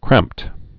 (krămpt)